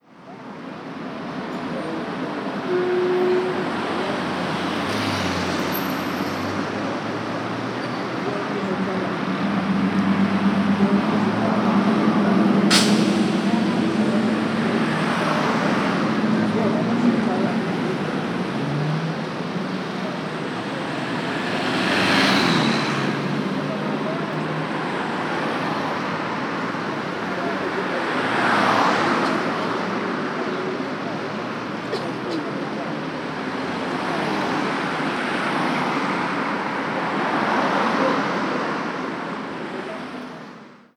Ambiente de una calle madrileña
tráfico toser tos ambiente auto automóvil calle chirriar chirrido circulación ciudad coche
Sonidos: Transportes Sonidos: Ciudad